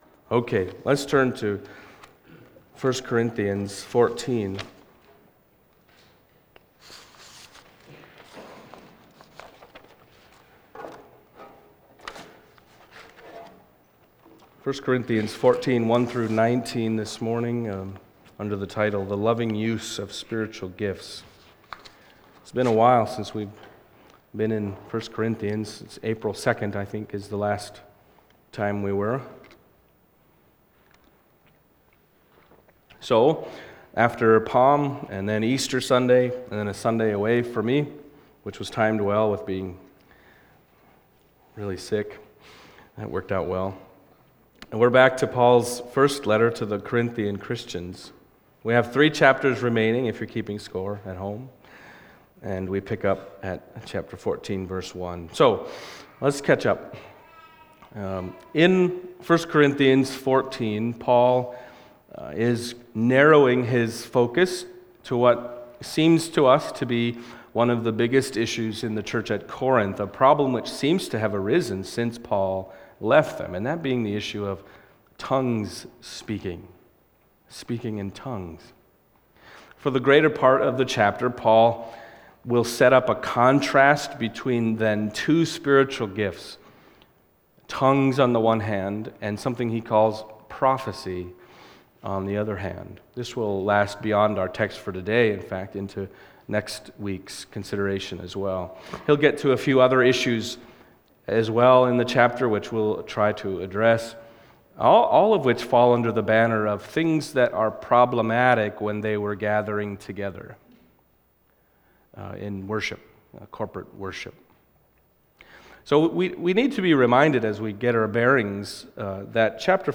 1 Corinthians Passage: 1 Corinthians 14:1-19 Service Type: Sunday Morning